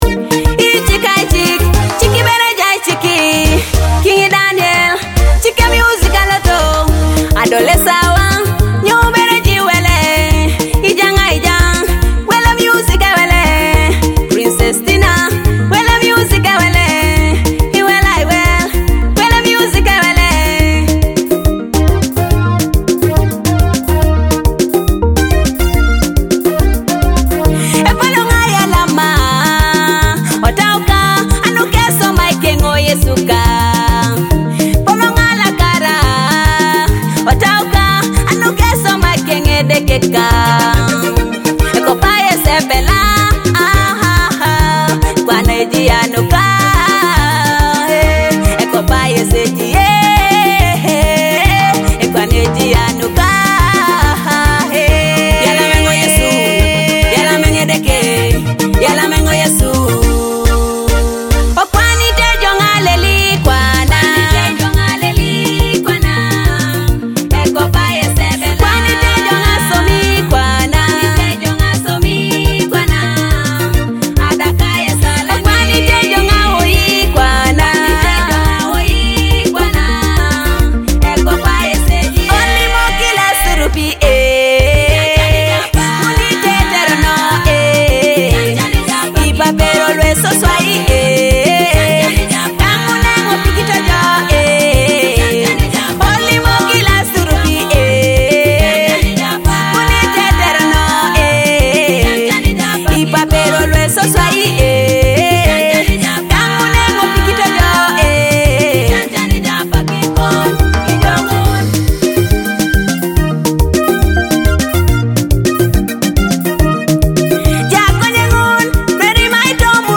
a heart-touching gospel song of gratitude and victory.
soul-stirring gospel song